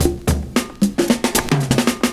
112PERCS05.wav